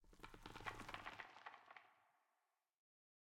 Minecraft Version Minecraft Version 25w18a Latest Release | Latest Snapshot 25w18a / assets / minecraft / sounds / block / pale_hanging_moss / pale_hanging_moss9.ogg Compare With Compare With Latest Release | Latest Snapshot
pale_hanging_moss9.ogg